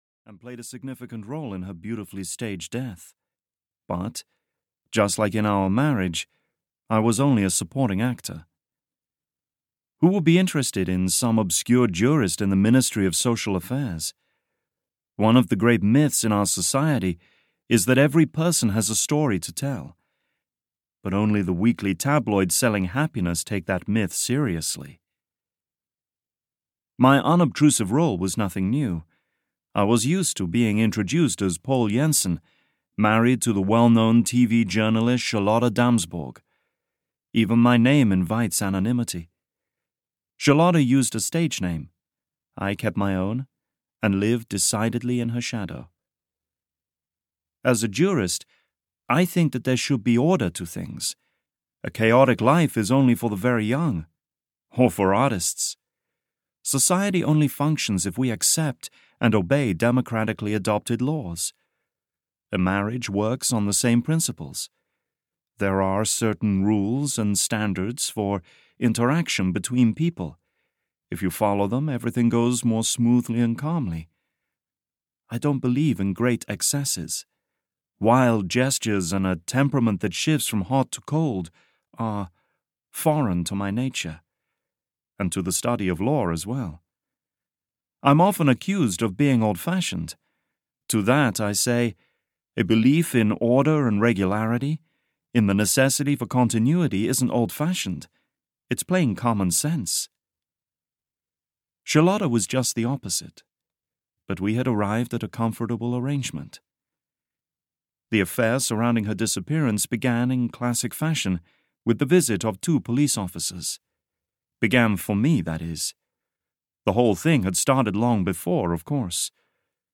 The Sardine Deception (EN) audiokniha
Ukázka z knihy